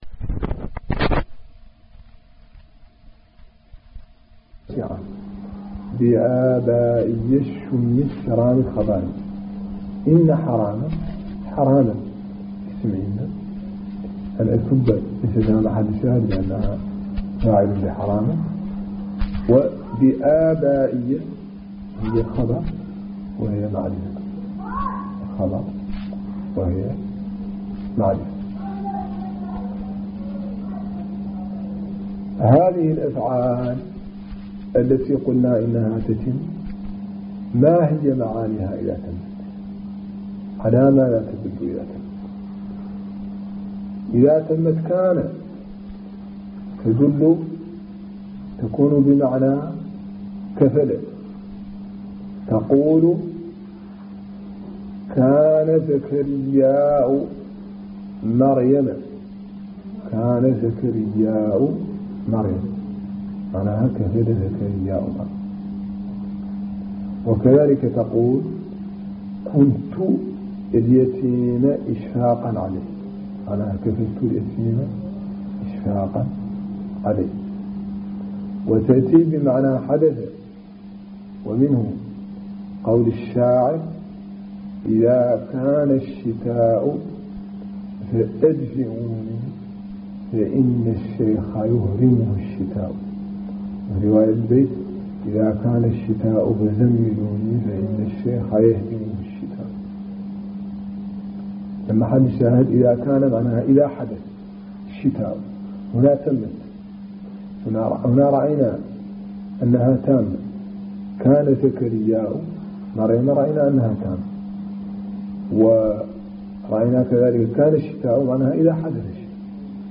المحاضرة